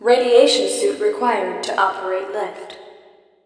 VOICES / COMPUTER